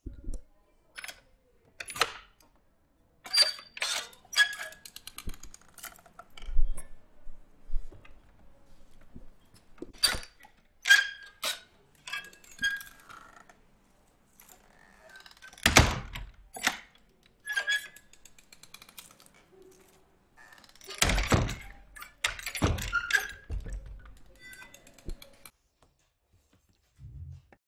Obraz w ruchu. Interwencja: Audioprzewodnik